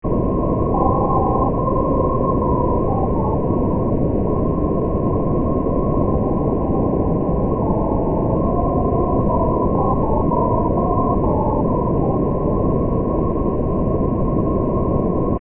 Demo 4 plays a melody with dichotic pitch.
All notes are true dichotic pitch (SBR = 1).